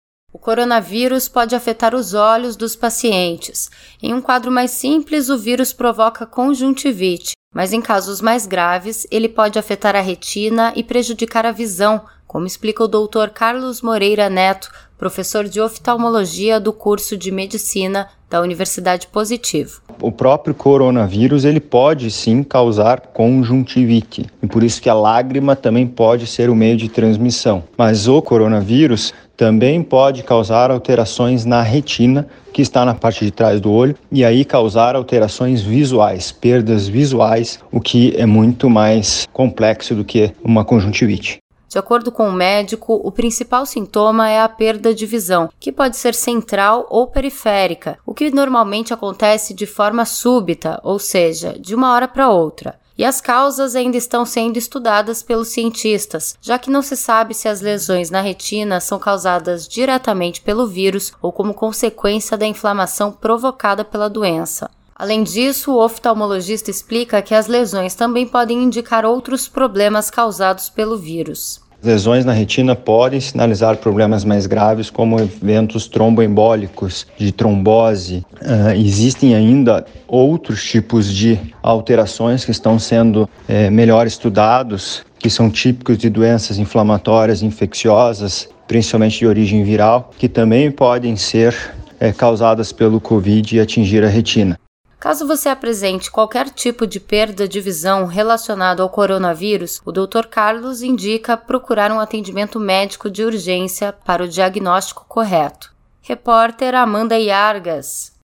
O coronavírus pode ser transmitido pelas lágrimas e também afetar a visão. Saiba como a Covid-19 ataca os olhos, na reportagem.